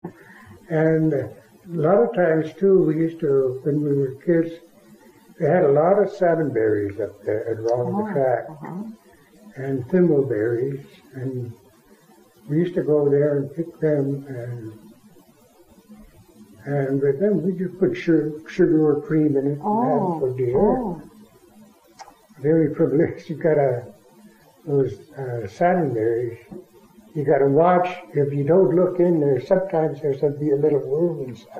In 2001, with funding from the National Park Service Historic Preservation program, the Jamestown S’Klallam Tribe conducted interviews with Tribal Elders and transformed these oral histories into the book “Sharing Our Memories: